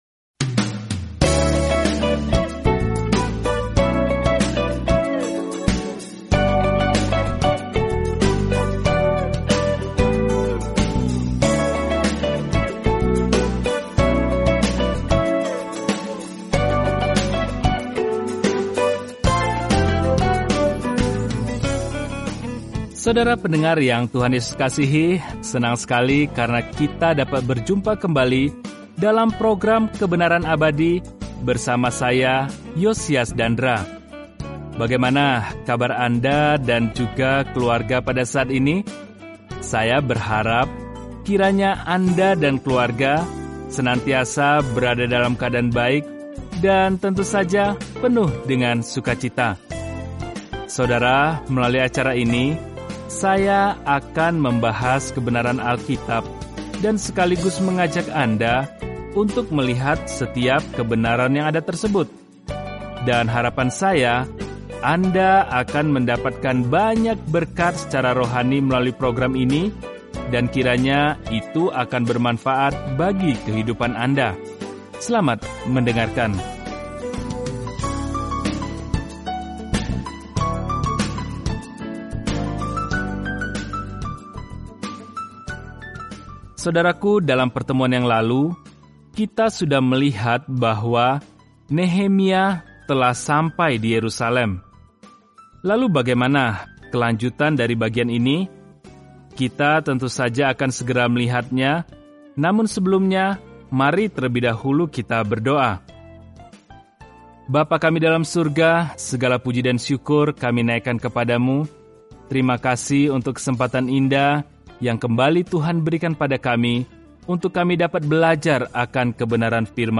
Firman Tuhan, Alkitab Nehemia 2:17-20 Nehemia 3:1-3 Hari 2 Mulai Rencana ini Hari 4 Tentang Rencana ini Ketika Israel kembali ke tanah mereka, kondisi Yerusalem buruk; seorang manusia biasa, Nehemia, membangun kembali tembok di sekeliling kota dalam buku Sejarah terakhir ini. Telusuri Nehemia setiap hari sambil mendengarkan pelajaran audio dan membaca ayat-ayat tertentu dari firman Tuhan.